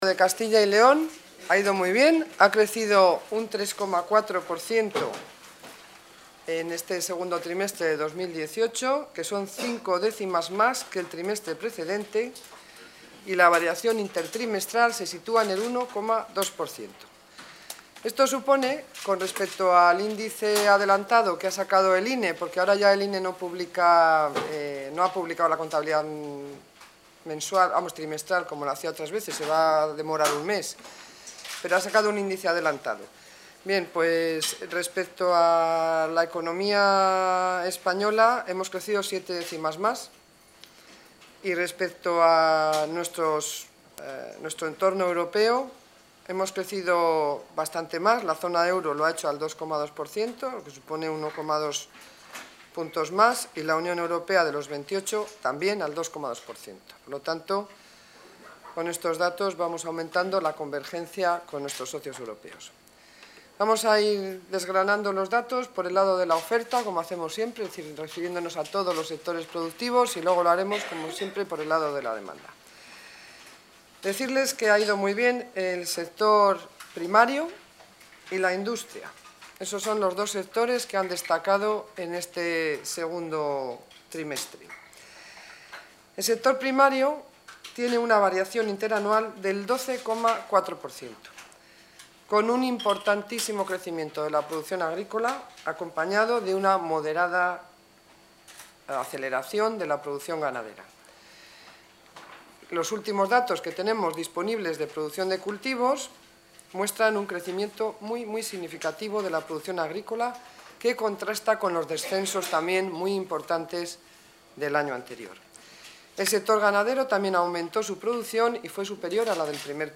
Consejera de Economía y Hacienda.